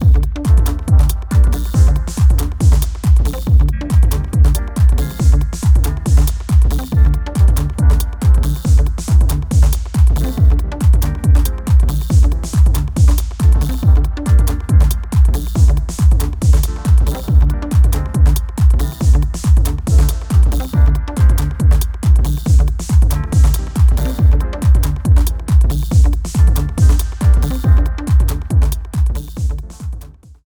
I got something resembling the kick, snare, rim, hats, low tom, clap anyway…